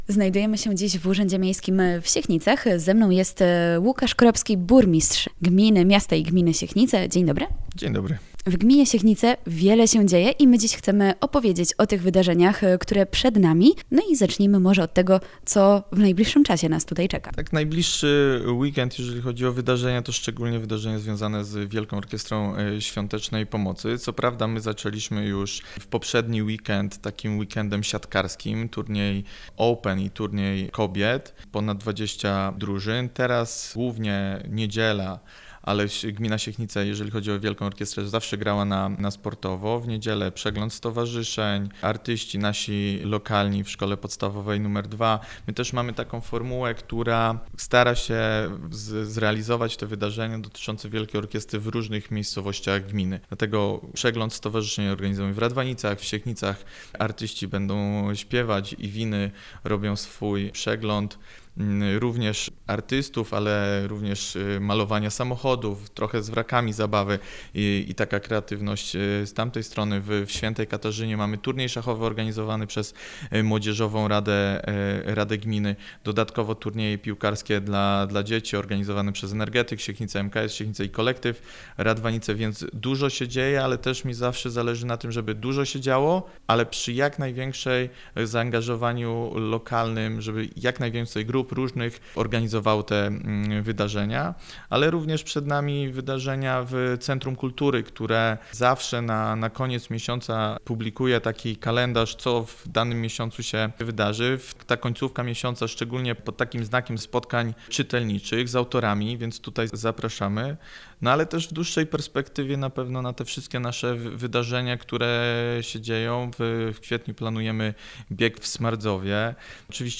Burmistrz-Siechnic-Lukasz-Kropski.mp3